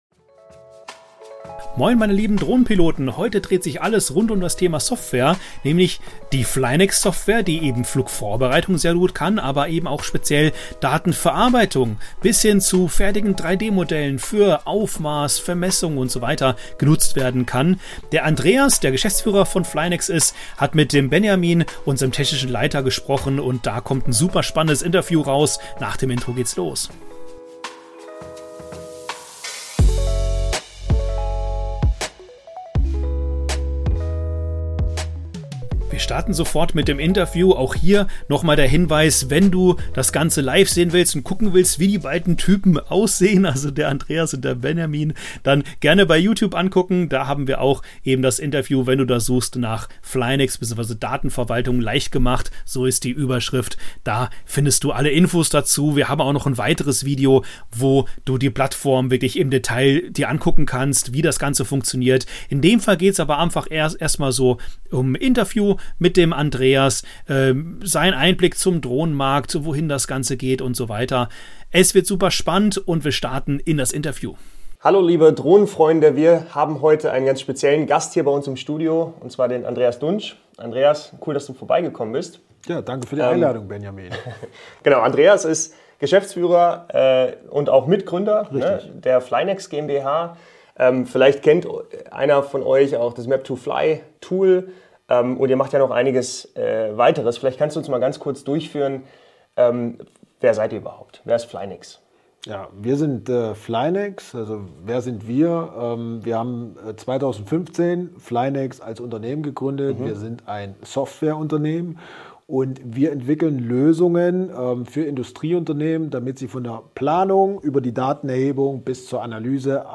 Drohnen-Talk: Interview mit Gründer von Drohnenplattform ~ Return To Home – Der Drohnenpodcast Podcast